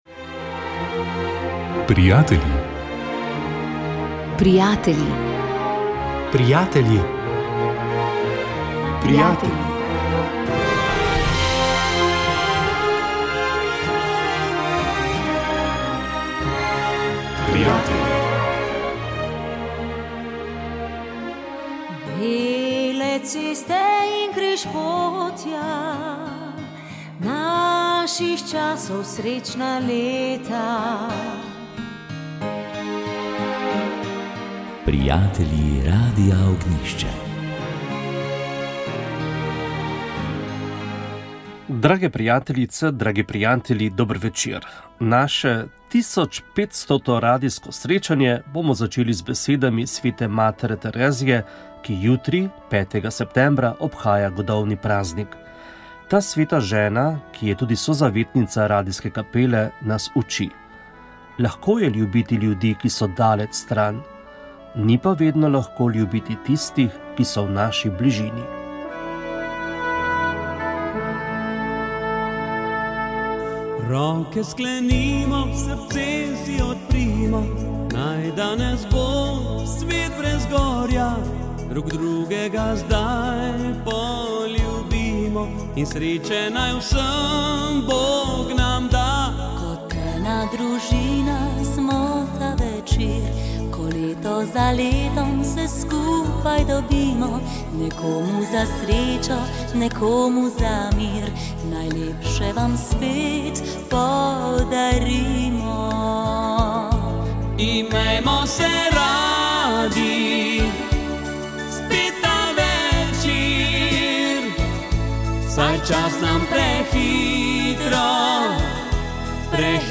V nocojšnji jubilejni oddaji PRO - 1.500. - ste slišali nekaj odmevov na oddajo PRO in sam Radio Ognjišče s strani naših PRO-jevcev, ki smo jim poslali pismo s prošnjo za sodelovanje. S klicem v oddajo pa ste lahko sodelovali tudi drugi.